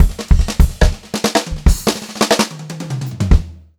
144SPFILL2-R.wav